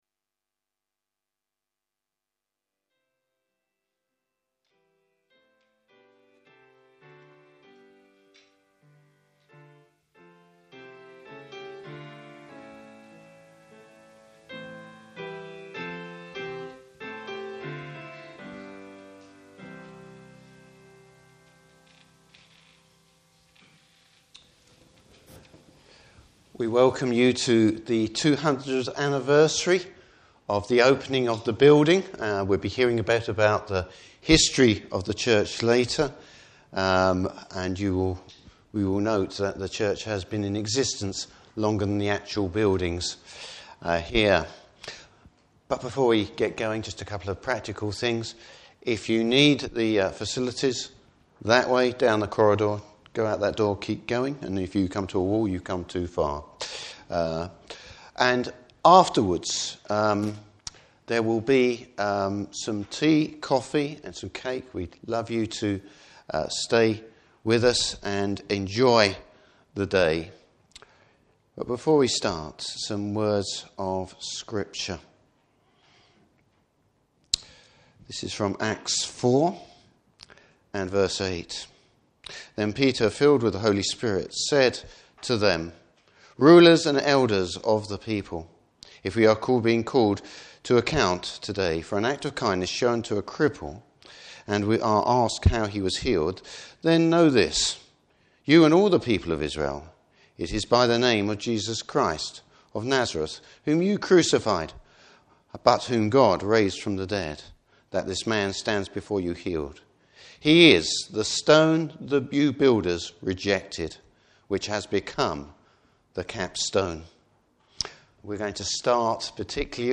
200th Anniversary Service and message.